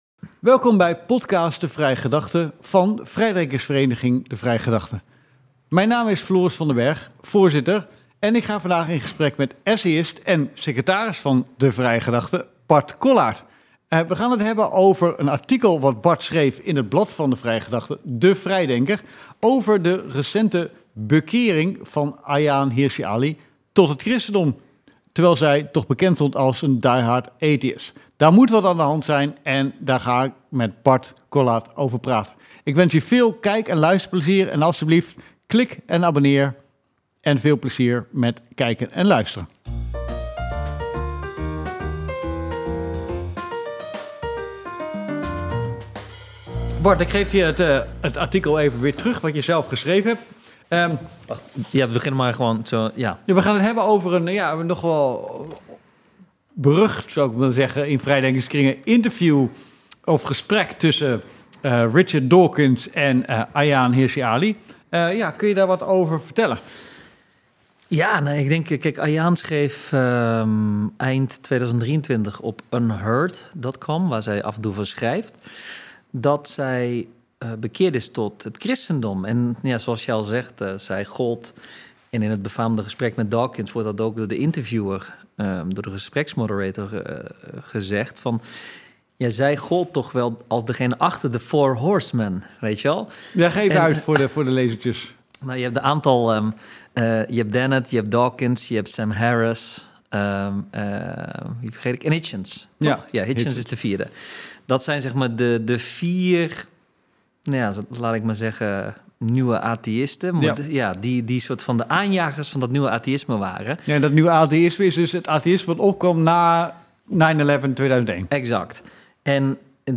Deze podcasts zijn opgenomen met beeld.